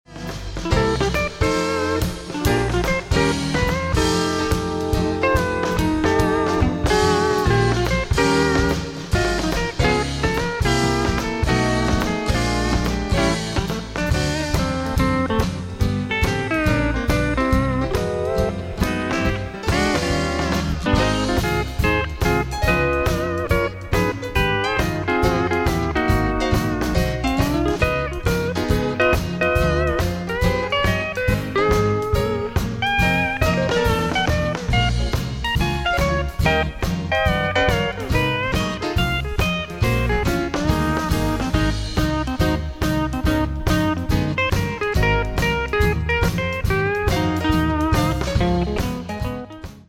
guitar
keyboards
violin and mandolin